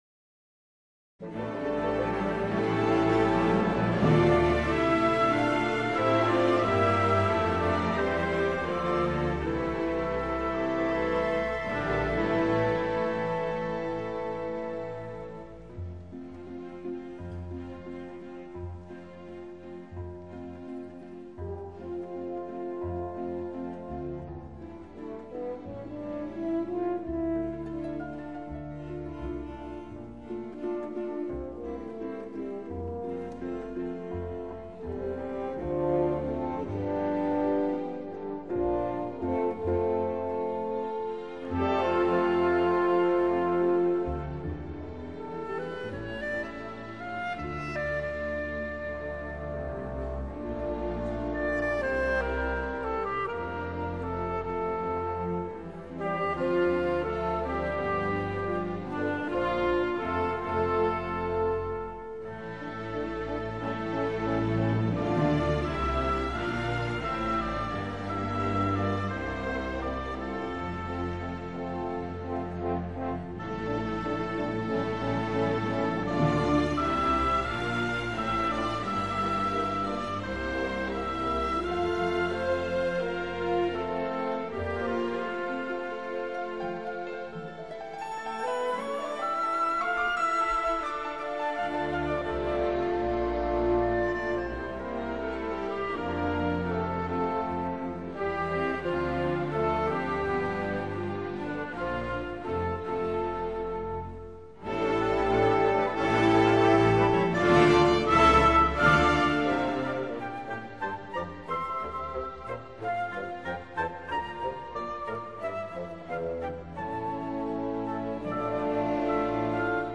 交响乐团演绎流行音乐带有很浓厚的交响乐手法，如配器华丽，